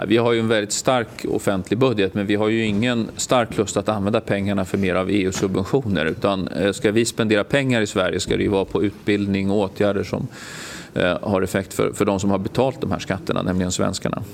Idag kunde vi höra Anders Borg svara på frågor om EU:s budget i Dagens Eko.